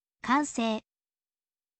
kansei